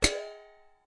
铃铛/锣鼓 " 不锈钢碗
描述：一个不锈钢碗，上面有自己的盖子。
Tag: 打击乐器 不锈钢